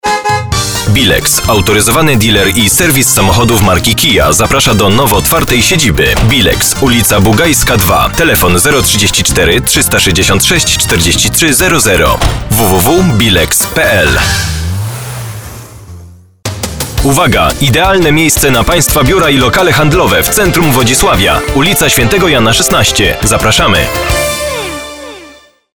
Professioneller polnischer Sprecher für TV / Rundfunk / Industrie.
Sprechprobe: Industrie (Muttersprache):
polish voice over artist